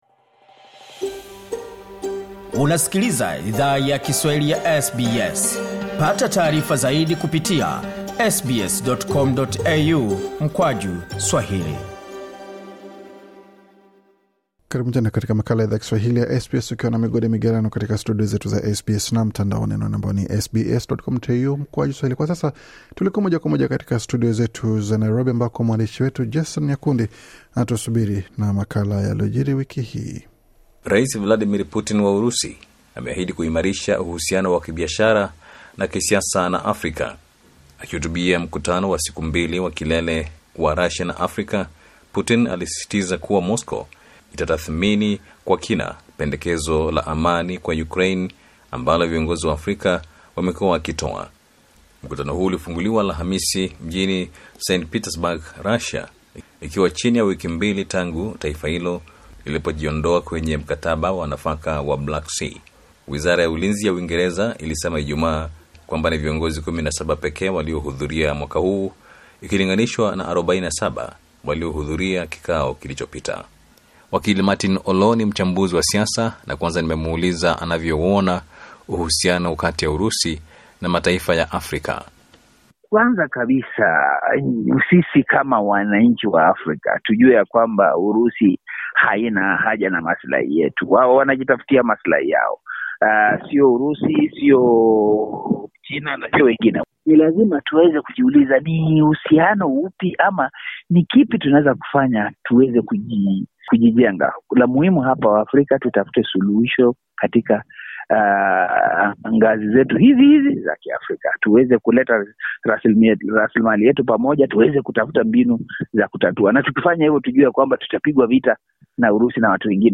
Katika mazungumzo maalum na SBS Swahili, alifafanua sababu za Urusi kuandaa kongamano hilo.